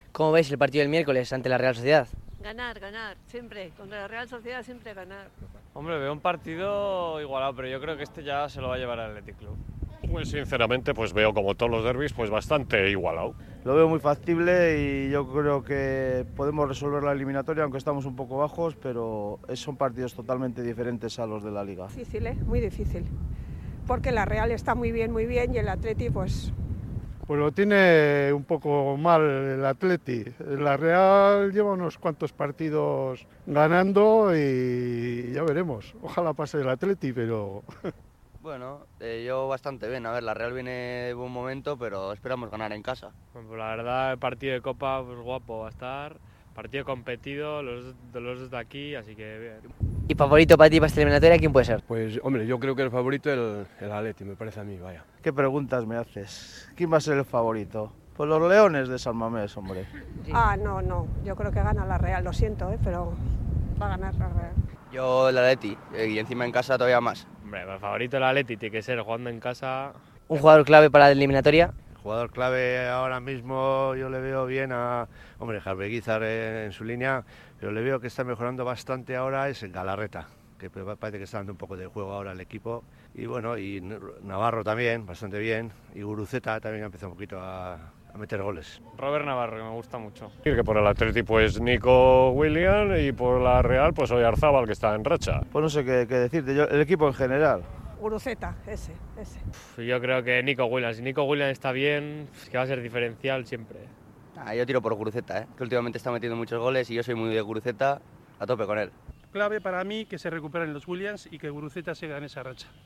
Hablamos con la afición rojiblanca: sensaciones y pronósticos para la eliminatoria
A dos días del partido de Copa ante la Real Sociedad, la encuesta callejera ha dejado un mensaje repetido: ilusión, respeto y una convicción que se escucha sin filtro.
ENCUESTA-DERBI.mp3